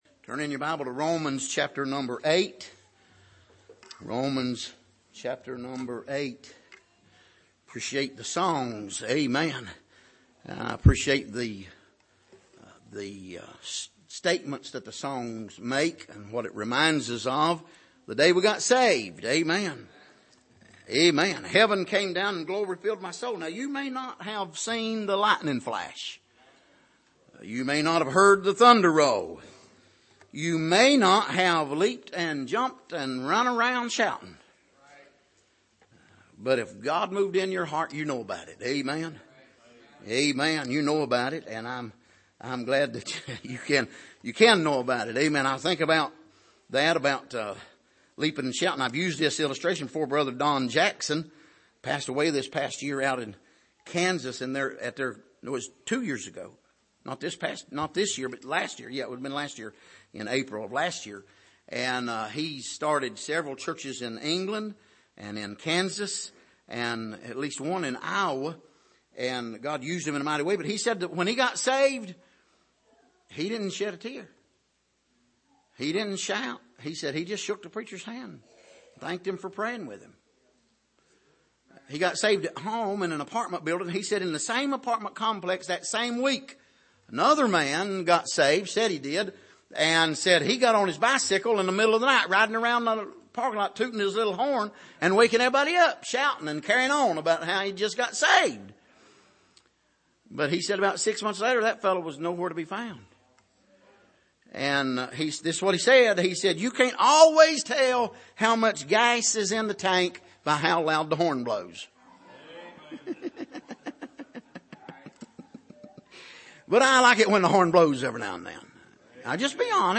Passage: Romans 7:12-25 Service: Sunday Morning